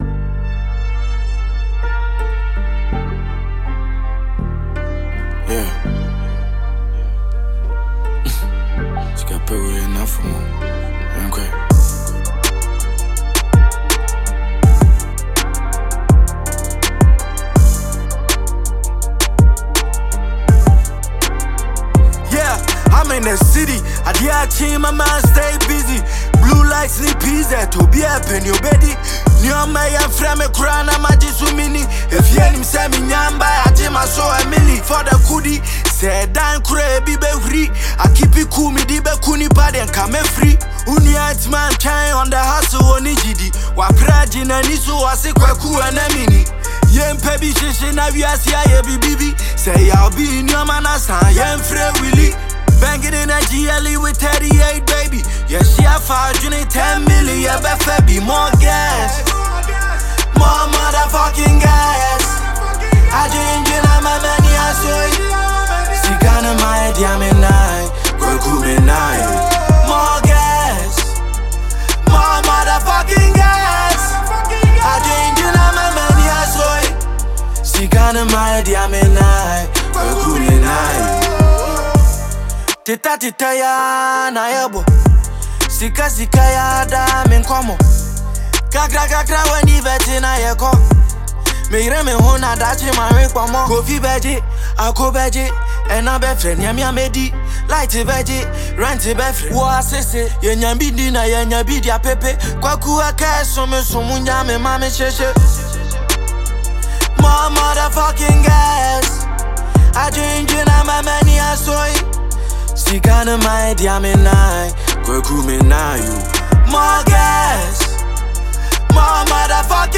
New one from Ghanaian rapper